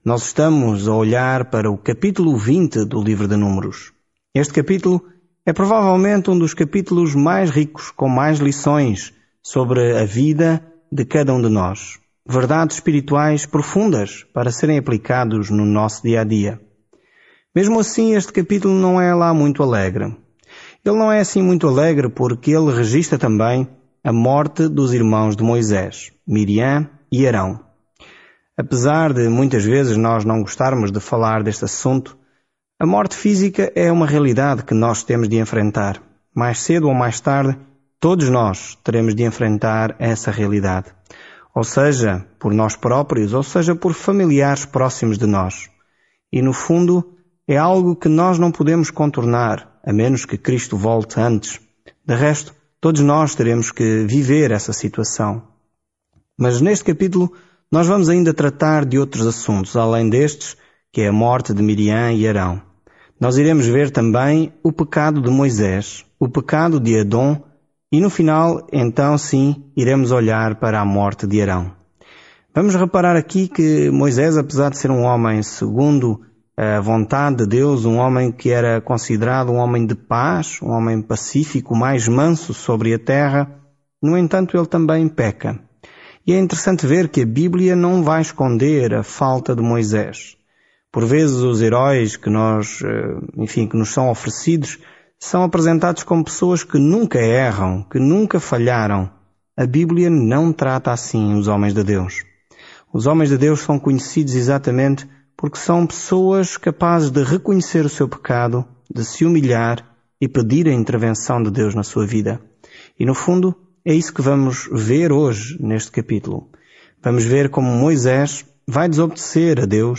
Escritura NÚMEROS 20 Dia 12 Iniciar este Plano Dia 14 Sobre este plano No livro de Números, estamos caminhando, vagando e adorando com Israel durante os 40 anos no deserto. Viaje diariamente por Números enquanto ouve o estudo em áudio e lê versículos selecionados da palavra de Deus.